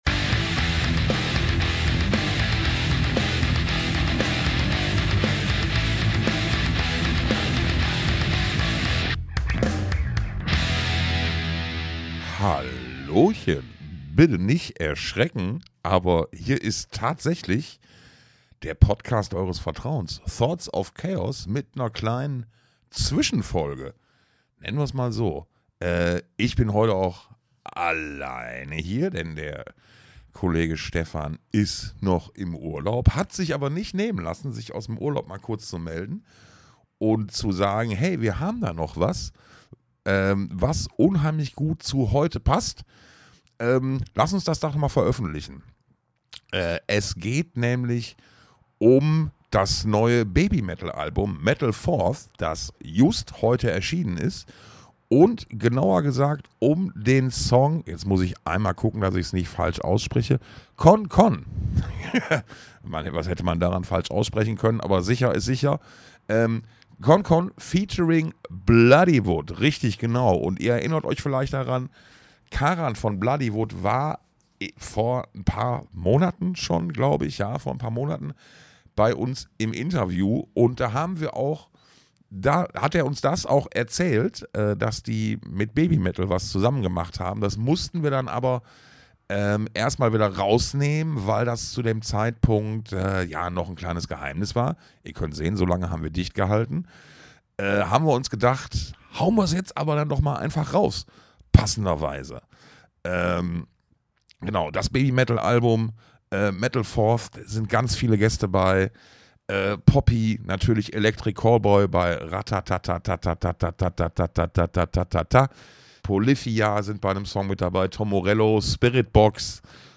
einen Auszug aus dem Gespräch